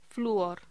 Locución: Flúor